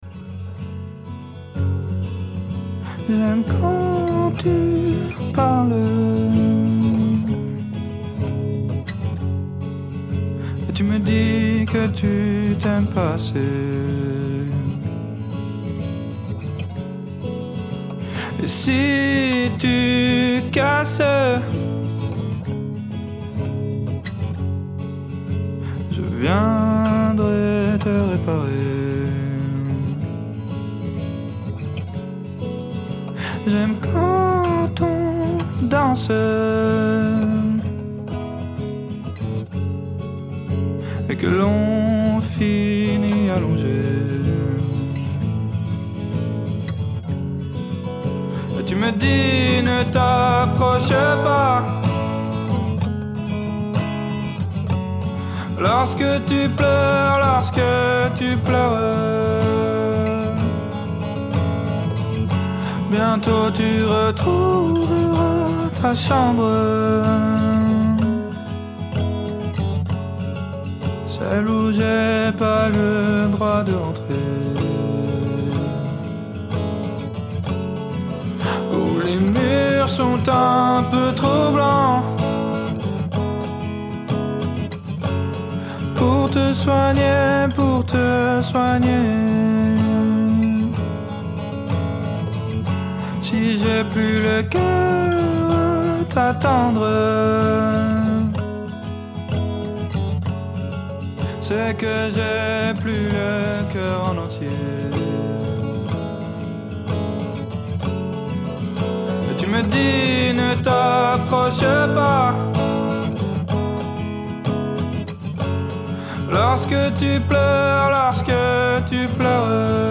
La Fraîche Liste est de retour pour une nouvelle saison en direct ! Un mercredi par mois, l'équipe de programmation vous invite à découvrir un.e artiste de sa sélection à travers un échange sur tout ce qui fait la musique, la création, la composition, et les émotions qui la guide.